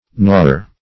knor - definition of knor - synonyms, pronunciation, spelling from Free Dictionary